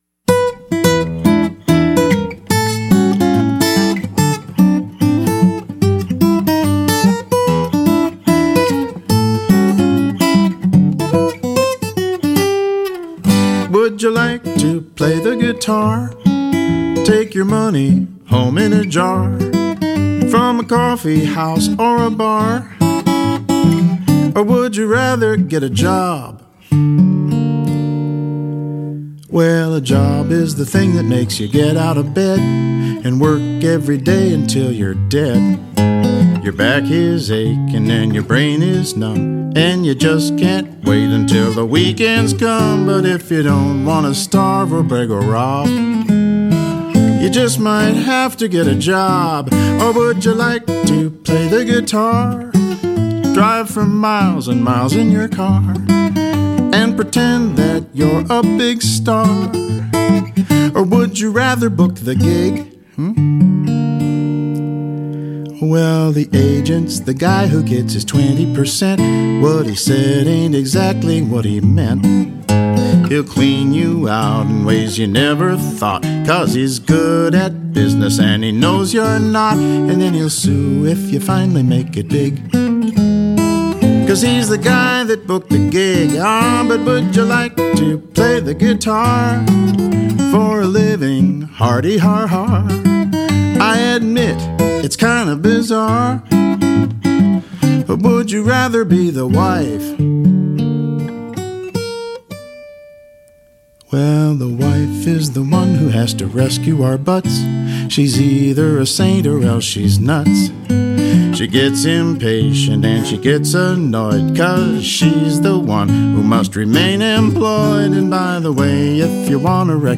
this is a parody of decades-old song